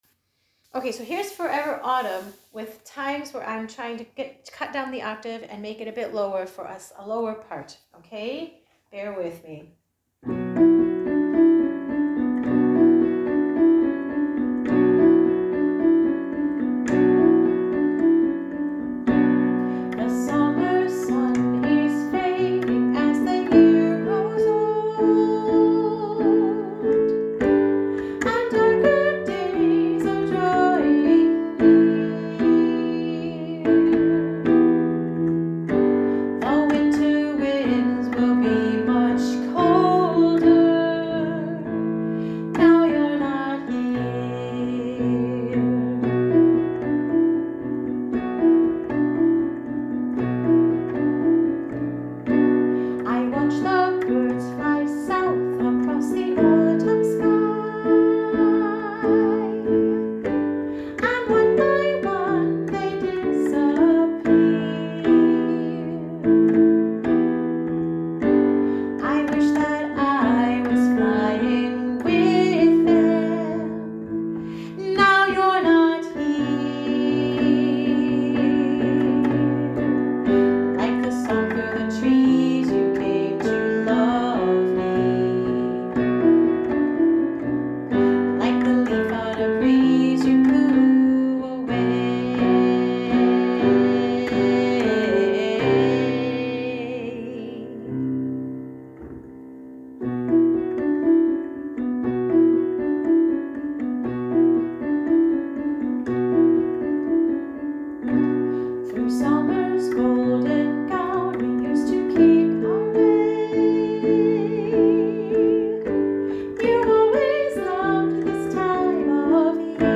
Harmony sing along tracks
forever-autumn-lower-harmonies.m4a